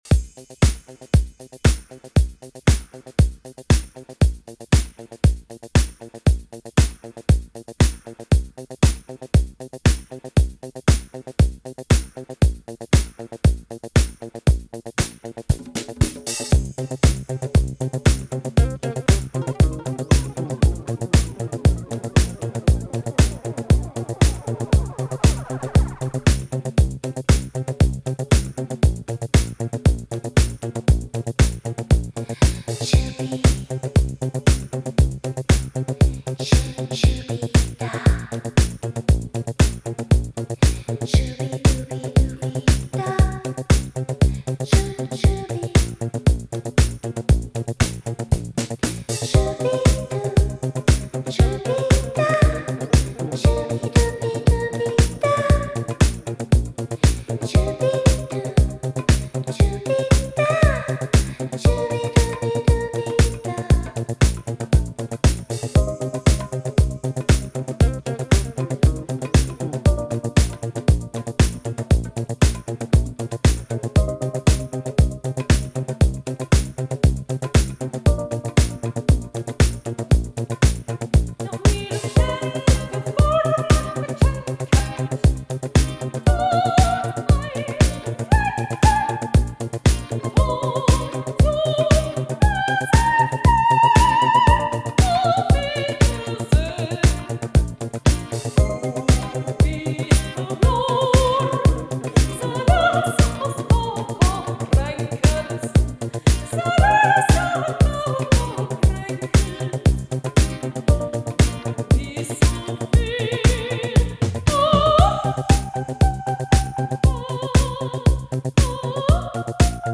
version disco